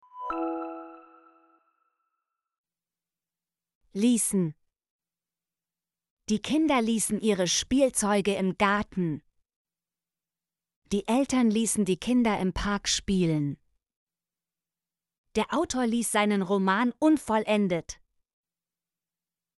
ließen - Example Sentences & Pronunciation, German Frequency List